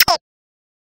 bullet gunfire passby ricochet whiz sound effect free sound royalty free Nature